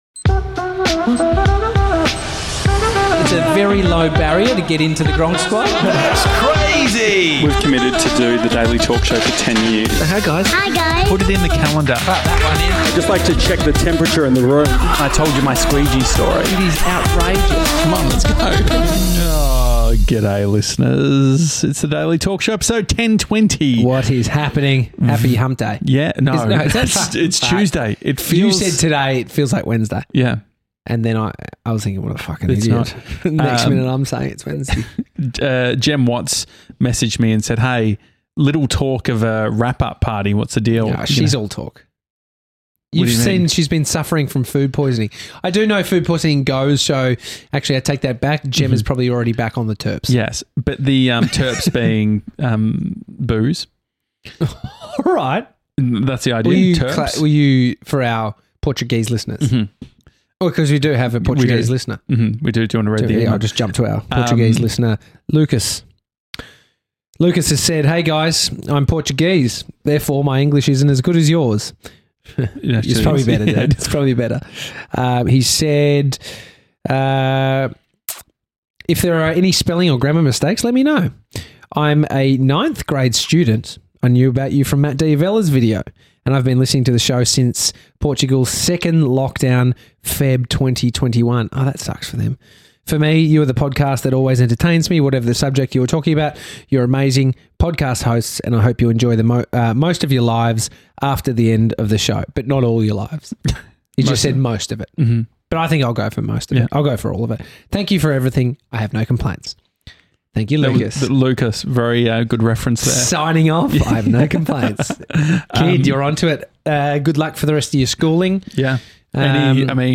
Regularly visited by guests and gronks!